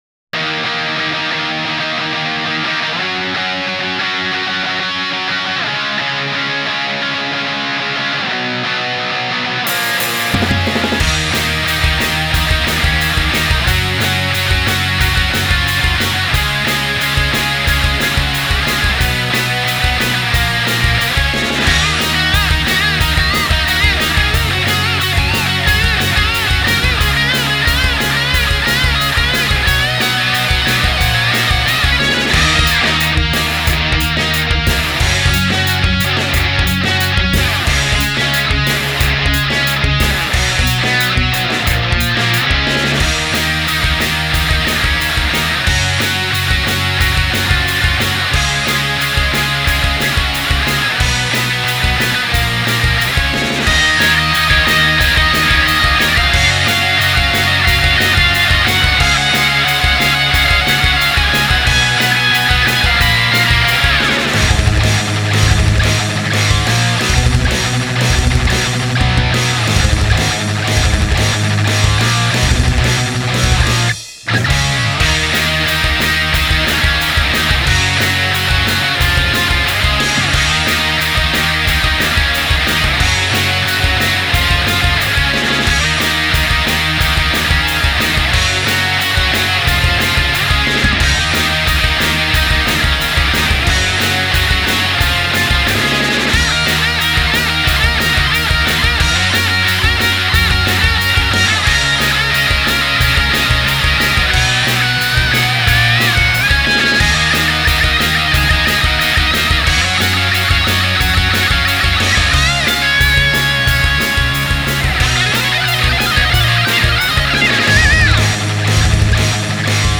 最初から最後まで突っ走り駆け抜け、泥臭くも爽快感のあるパンクロックサウ